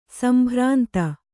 ♪ sambhrānta